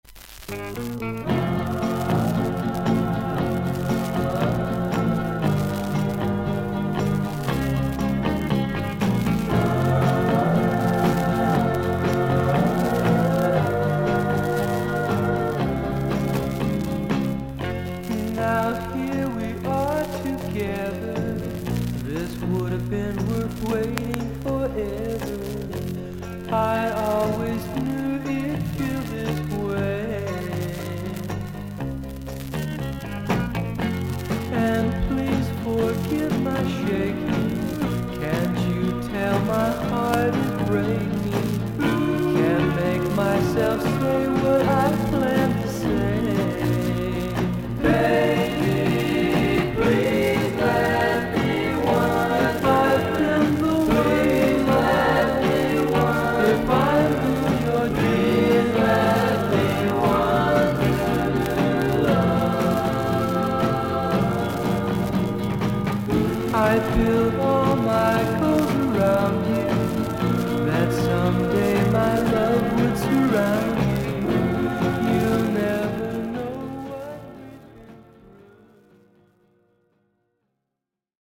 全体的にサーフィス・ノイズあり。少々軽いパチノイズの箇所あり。B面はサーフィス・ノイズが大きめにあります。